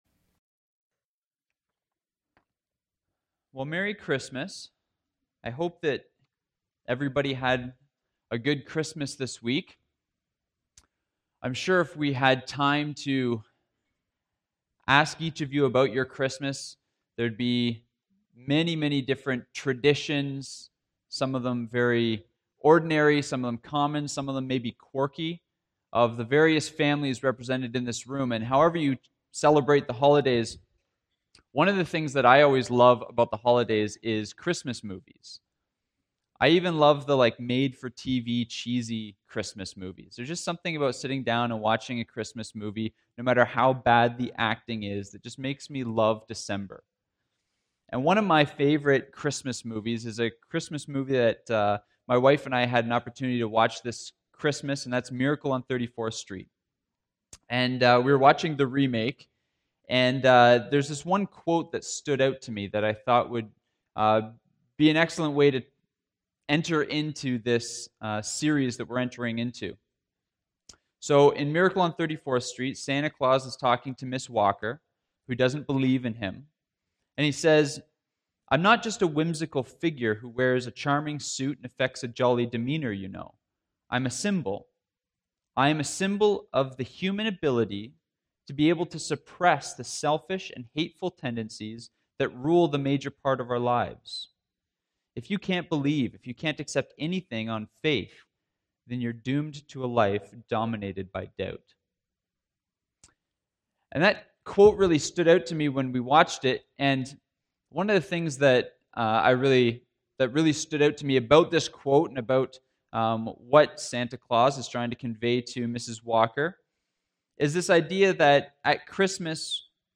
December 30 sermon